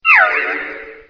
P3D-Legacy / P3D / Content / Sounds / Cries / 684.wav